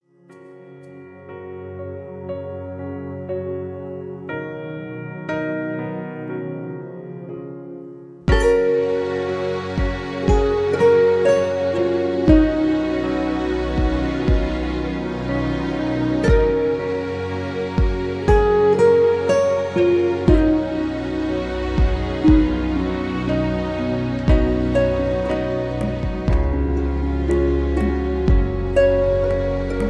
(Key-F#) Karaoke MP3 Backing Tracks
Just Plain & Simply "GREAT MUSIC" (No Lyrics).